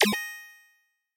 Звук пройденного чекпоинта в видеоигре